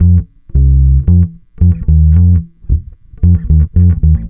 Track 15 - Bass 05.wav